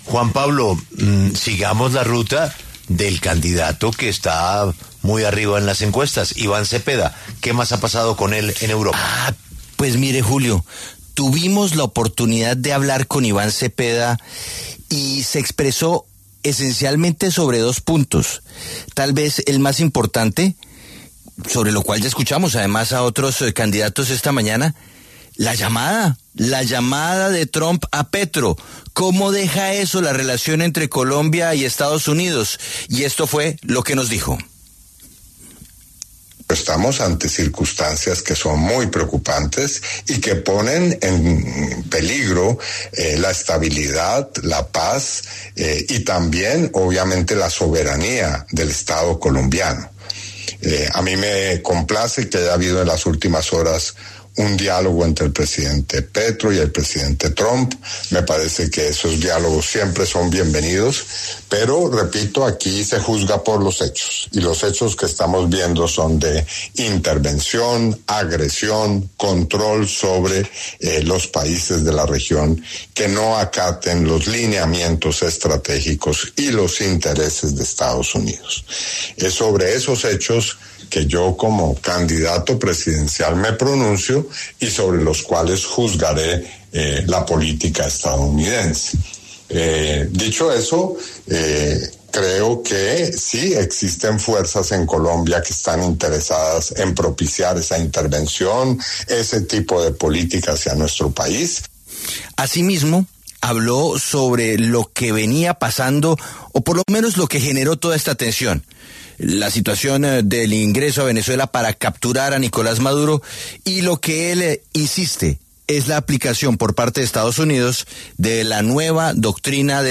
Desde España, La W conversó con el precandidato presidencial Iván Cepeda, quien se mostró complacido por la llamada telefónica entre los presidentes de Colombia y Estados Unidos, Gustavo Petro y Donald Trump, en la que hablaron de diferentes temas.